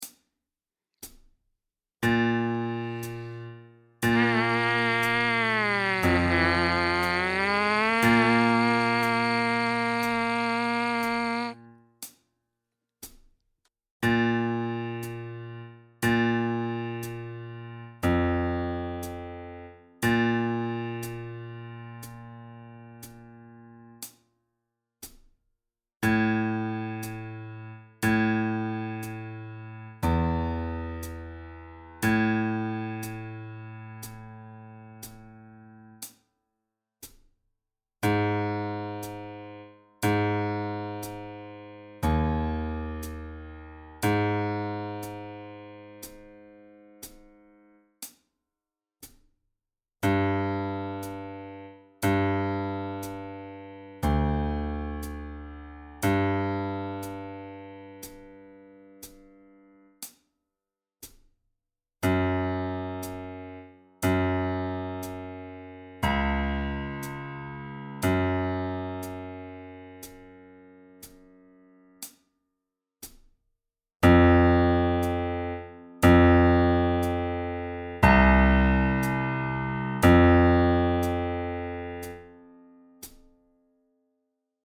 Mouthpiece Buzzing
Mouthpiece Buzzing #1 play along